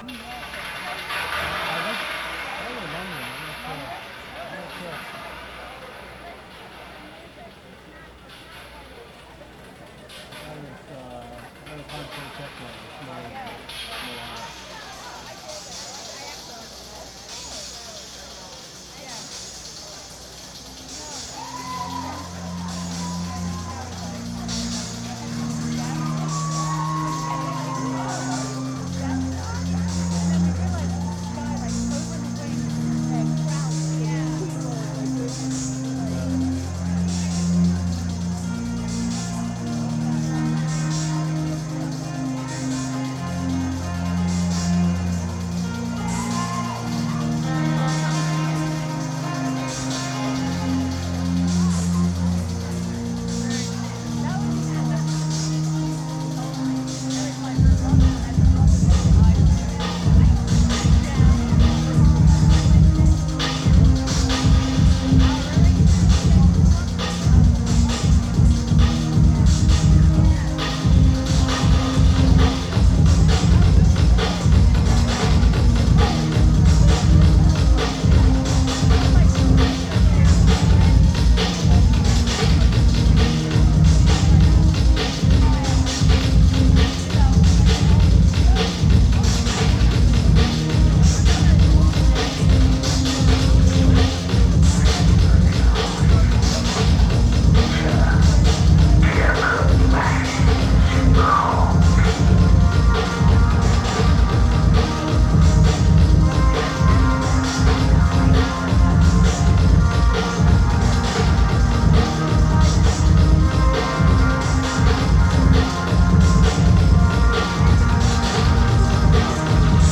venue Avalon Ballroom